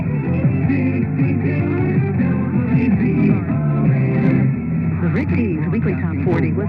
wzonjingle.mp3